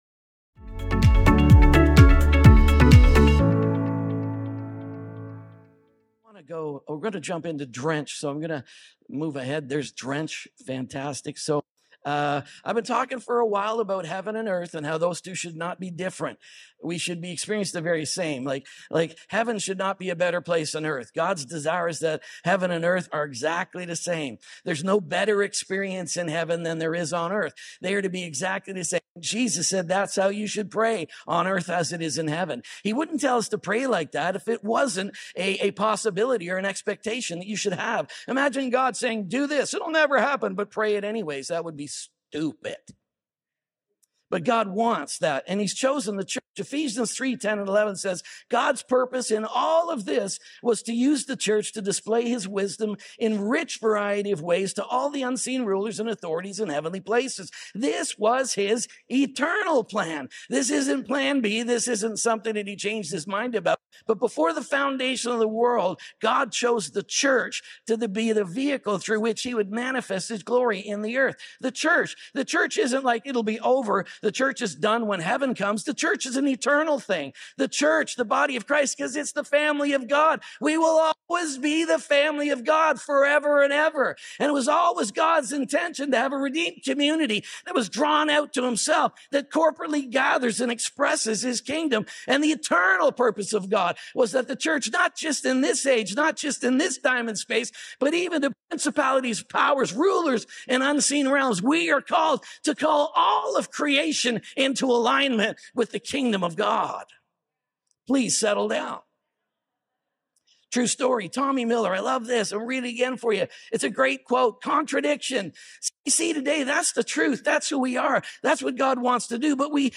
SATAN COMES TO CHURCH | DRENCH SERIES | SERMON ONLY .mp3